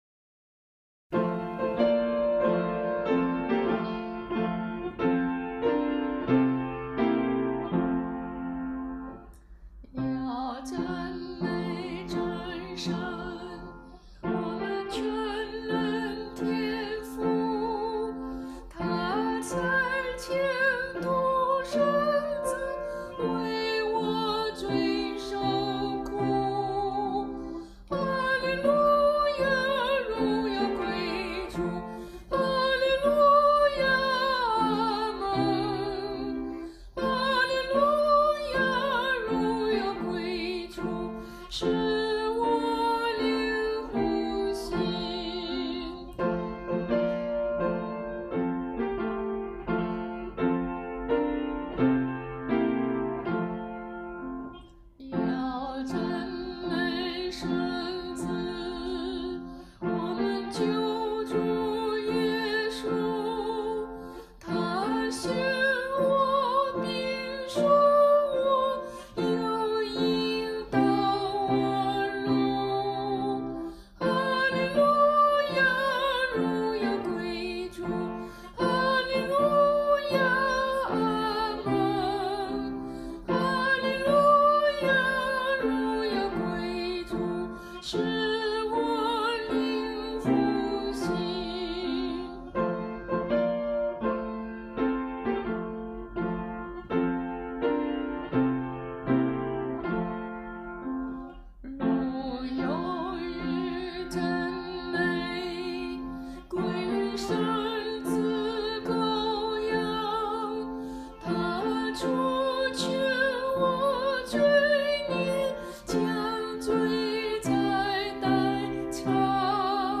伴奏
他为本诗所谱写的曲调，特别是副歌部分，旋律优美欢快，热烈活泼，使本诗插上歌声的翅膀，在一百多年的时间里广为传唱。